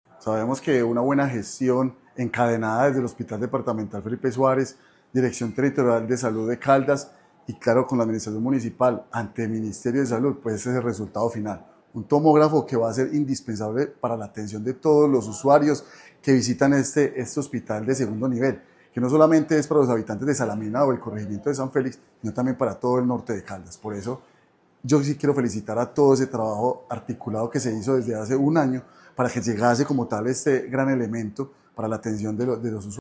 Manuel Fermín Giraldo, Alcalde de Salamina.
AUDIO-MANUEL-FERMIN-GIRALDO-ALCALDE-DE-SALAMINA-ENTREGA-TOMOGRAFO.mp3-online-audio-converter.com_.mp3